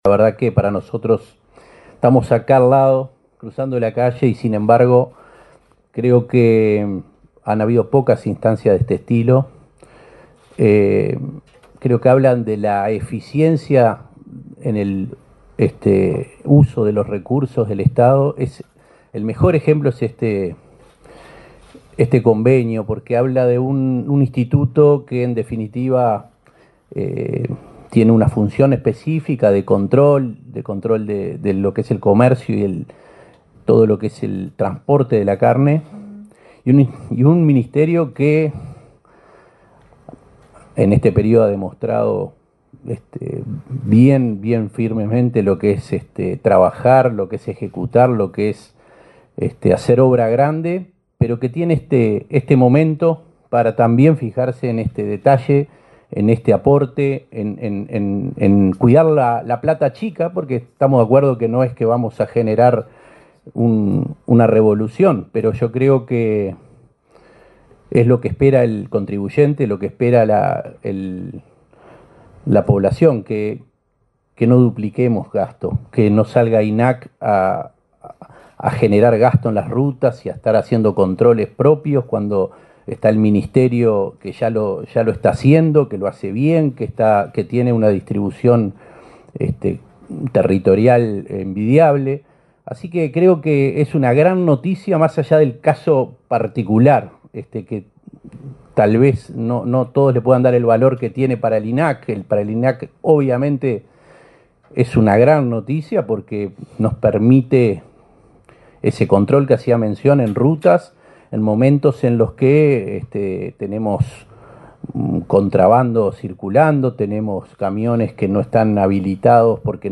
Palabras de autoridades en convenio entre INAC y MTOP
Palabras de autoridades en convenio entre INAC y MTOP 06/08/2024 Compartir Facebook X Copiar enlace WhatsApp LinkedIn El Ministerio de Transporte y Obras Públicas (MTOP) y el Instituto Nacional de Carnes (INAC) firmaron un convenio para reforzar los controles de vehículos que transportan carnes y derivados en el territorio nacional. Participaron el titular de la cartera, José Luis Falero, y el presidente del INAC, Conrado Ferber.